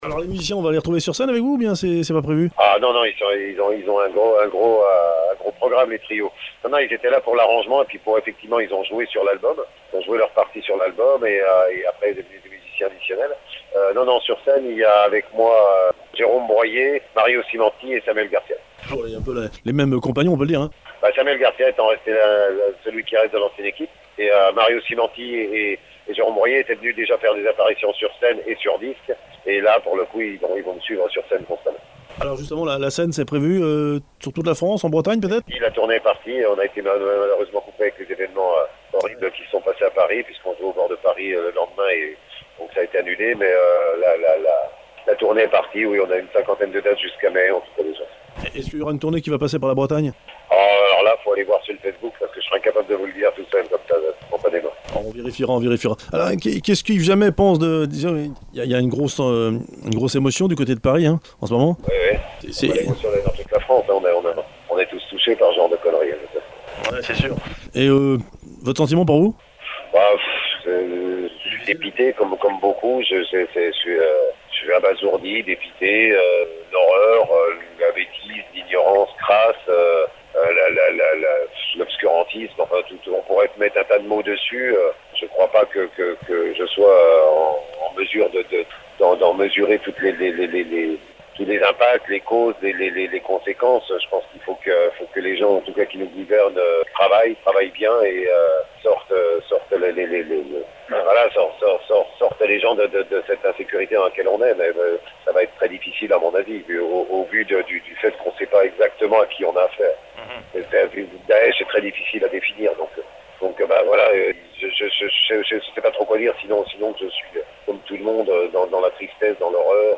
Interview d’Yves Jamait ( réalisée par téléphone juste après les attentats du 13 novembre 2015 à Paris)